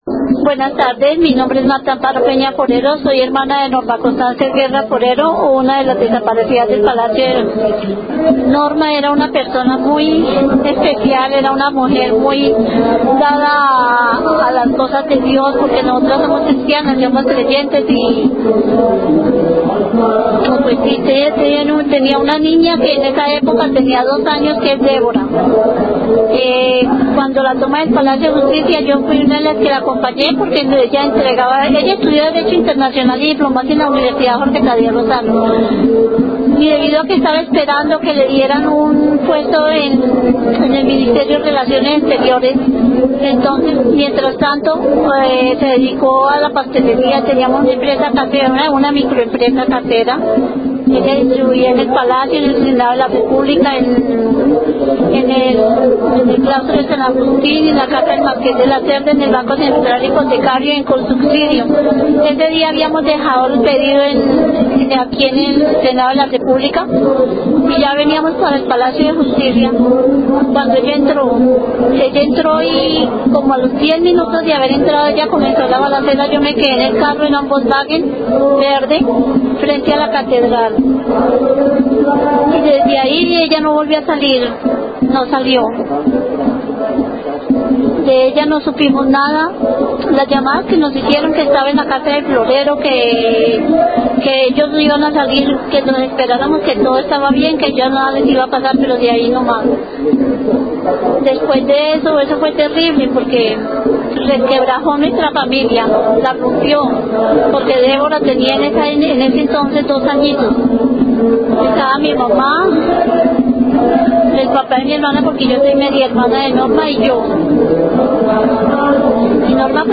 A través de las palabras y voces de los  familiares de las víctimas, recordamos a cada uno de los desaparecidos del Palacio de Justicia: